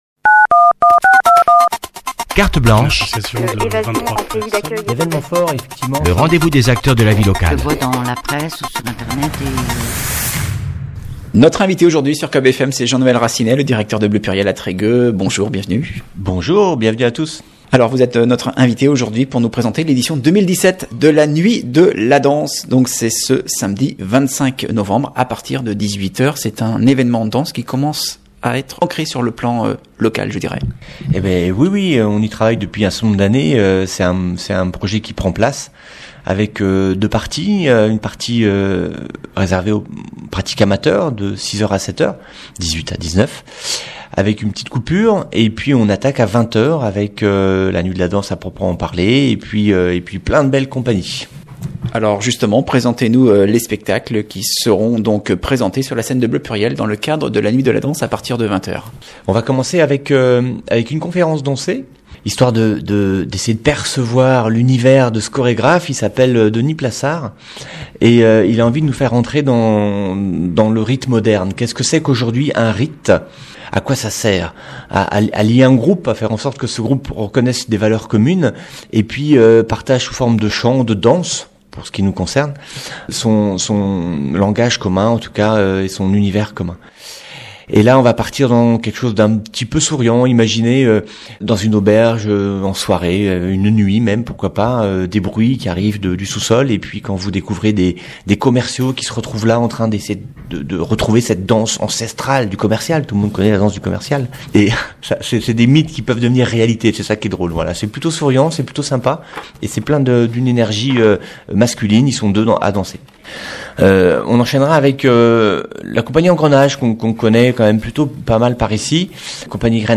Invité de la rédaction ce lundi